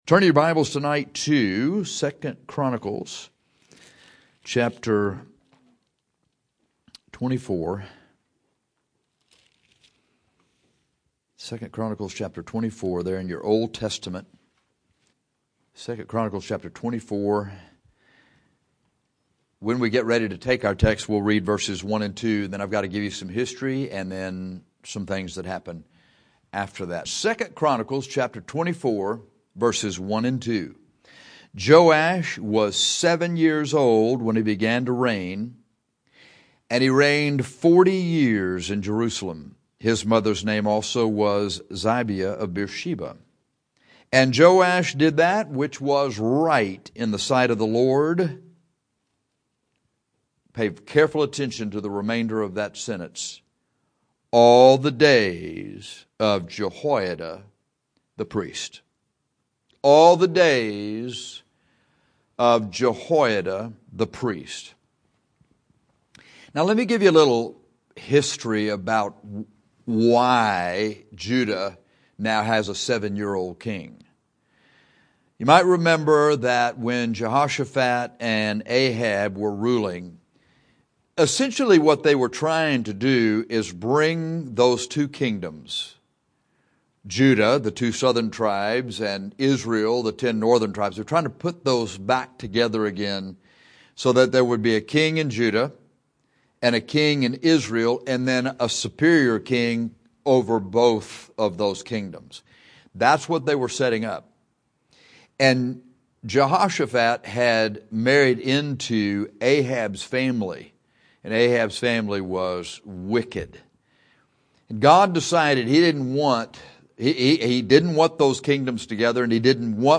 In this sermon we discuss the difference between the godly influences of the preacher and the sinful influences of the men of the world.